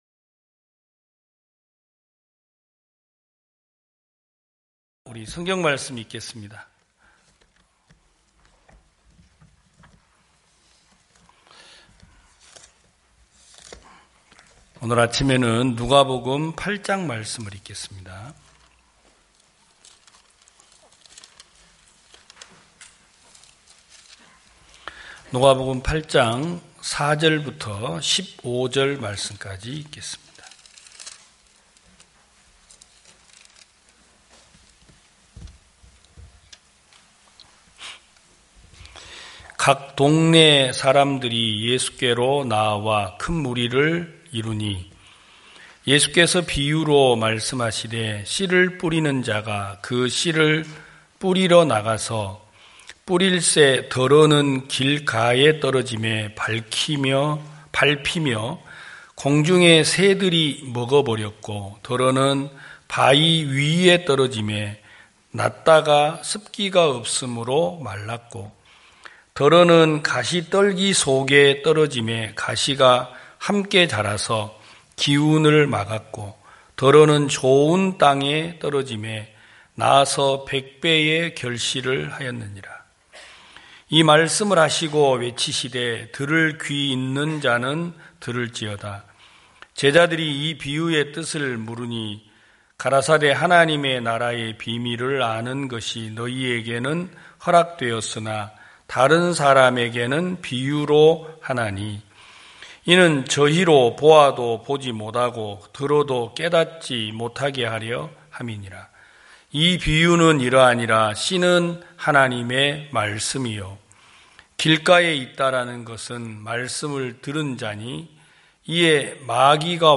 2021년 11월 14일 기쁜소식부산대연교회 주일오전예배
성도들이 모두 교회에 모여 말씀을 듣는 주일 예배의 설교는, 한 주간 우리 마음을 채웠던 생각을 내려두고 하나님의 말씀으로 가득 채우는 시간입니다.